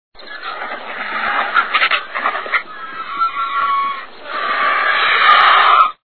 Sonido del Buitre leonado
buitreleonado.wav